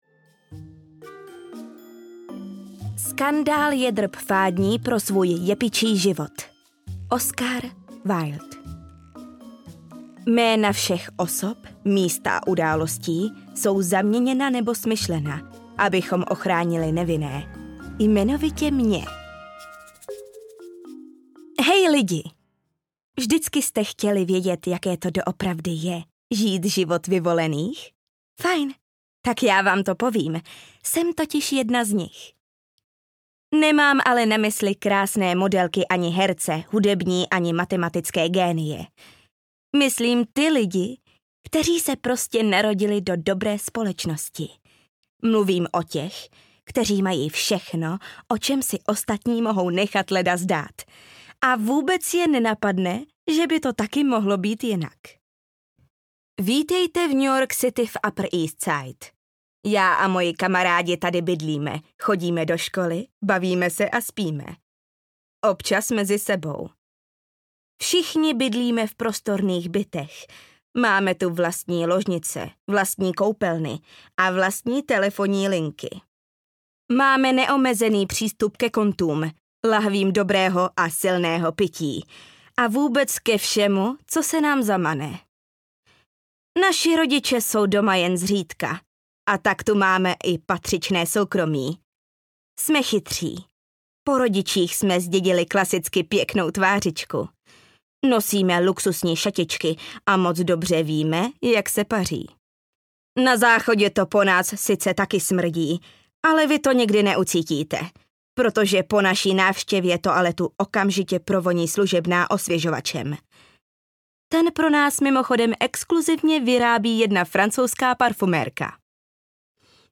Gossip Girl: Líbej mě audiokniha
Ukázka z knihy